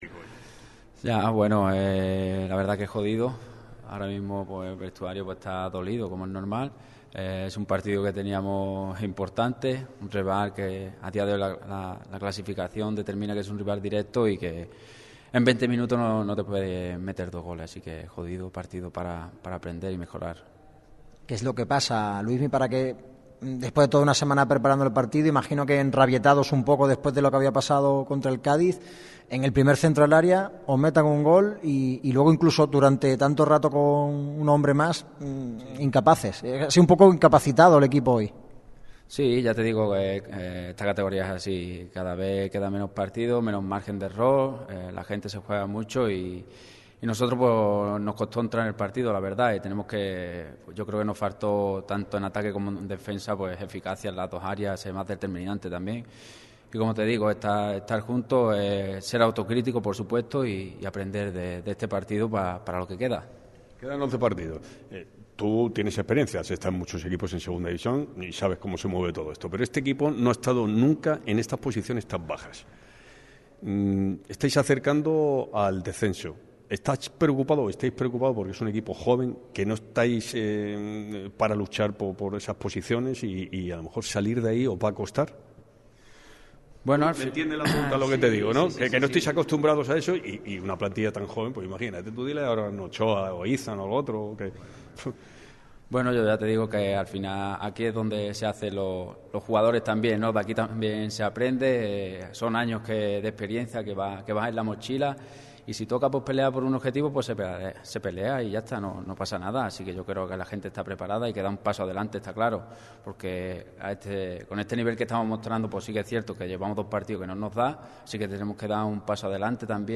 El centrocampista del Málaga CF, Luismi Sánchez, ha comparecido ante los medios en zona mixta tras la dura derrota del equipo ante el Albacete Balompié. El gaditano ha analizado el estado anímico del vestuario, la polémica arbitral, el próximo partido, entre otros titulares destacados.